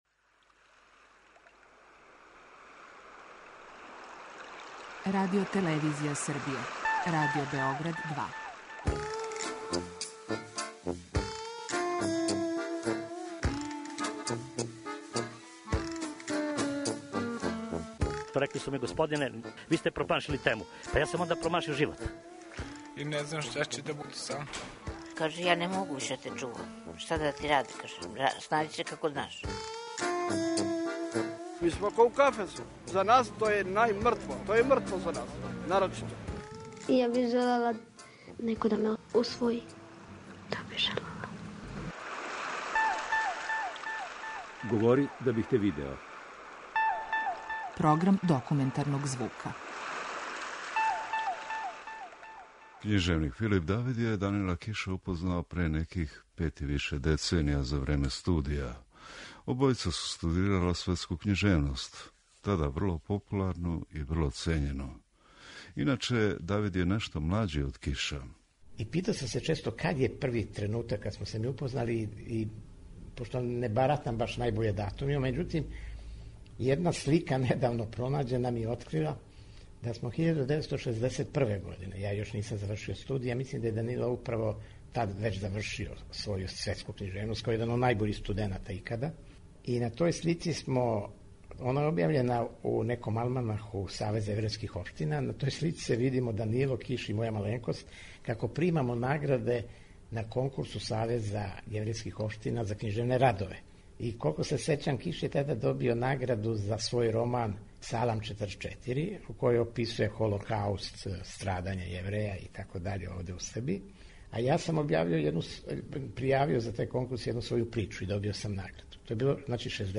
Документарни програм
преузми : 10.71 MB Говори да бих те видео Autor: Група аутора Серија полусатних документарних репортажа, за чији је скупни назив узета позната Сократова изрека: "Говори да бих те видео".
O Данилу Кишу , његовој личности и књижевнoм делу писца који је још за живота сматран великим уметником речи - говориће књижевник Филип Давид, који се са Кишом много дружио и добро разумео.